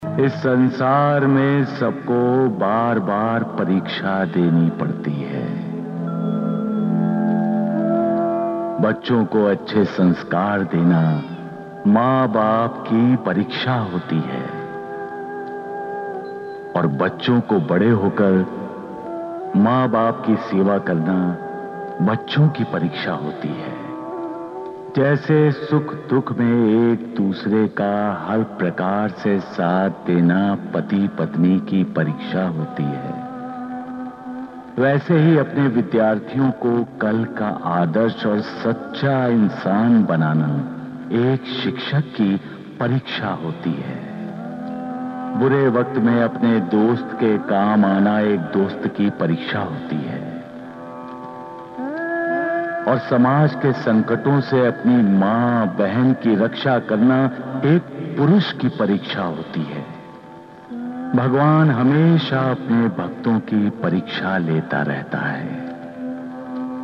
Some dialogues from the Ramanand Sagar's SAIBABA,